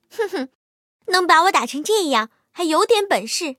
M22蝉中破语音.OGG